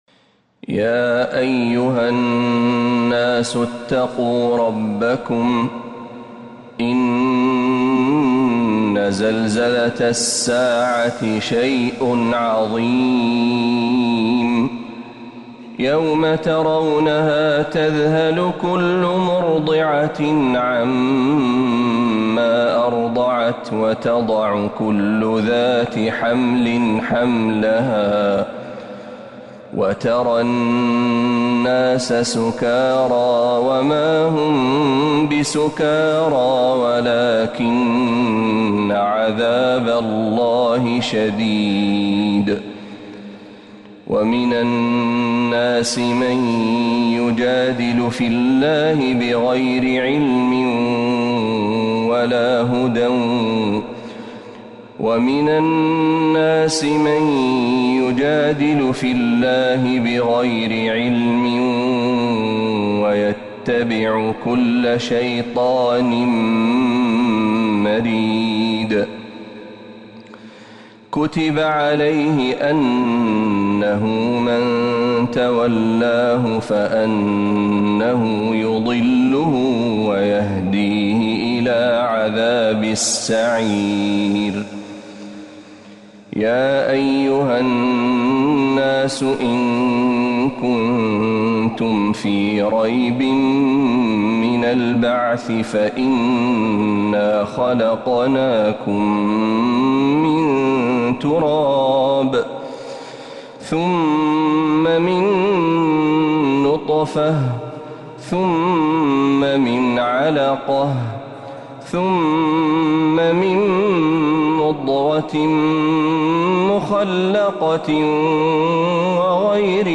سورة الحج كاملة من الحرم النبوي > السور المكتملة للشيخ محمد برهجي من الحرم النبوي 🕌 > السور المكتملة 🕌 > المزيد - تلاوات الحرمين